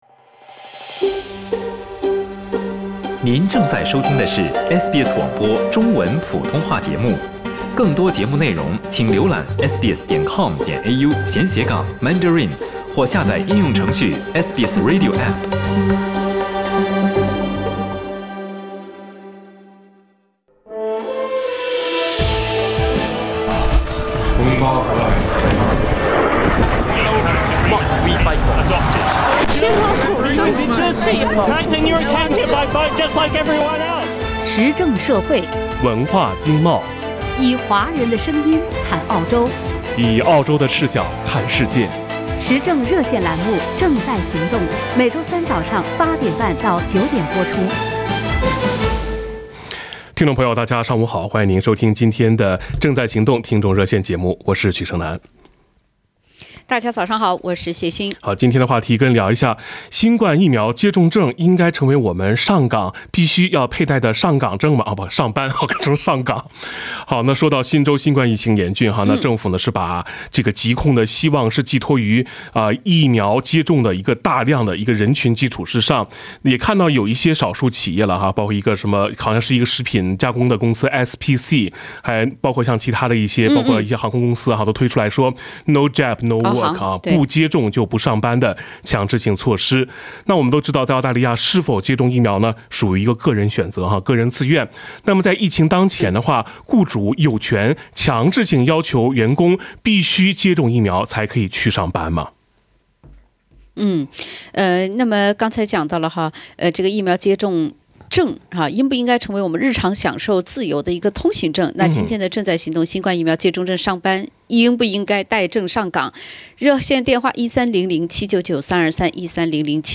（点击封面图片，收听热线回放）